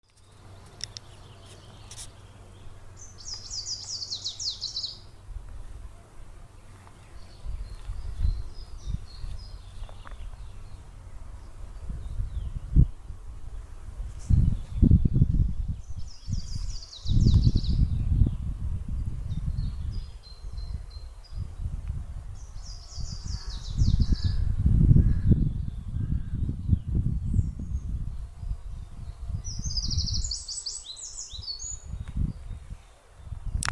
Zaļais ķauķītis, Phylloscopus trochiloides
Ziņotāja saglabāts vietas nosaukumsKolka
Zaļais ķauķītis perfekti imitē paceplīti, putns novērots vizuāli dziedam abās dziesmās